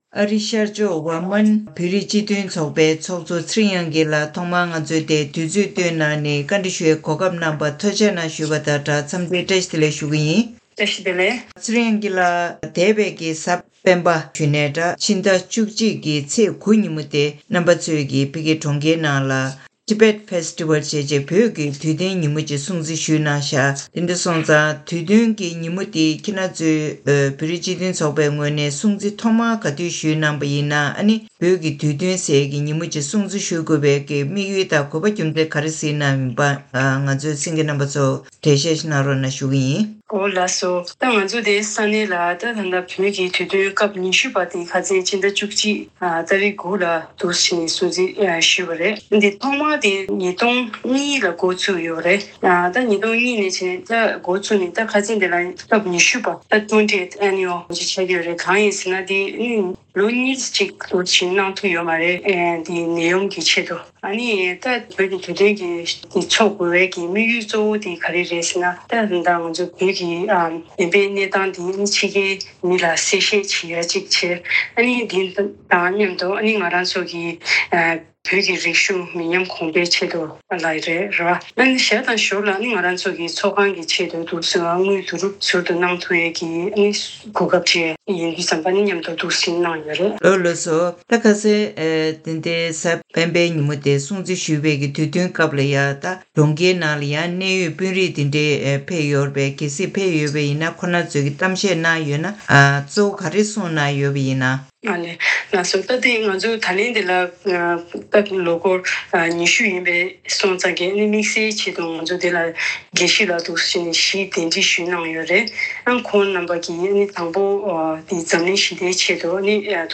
གནས་འདྲིའི་ལེ་ཚན་ནང་།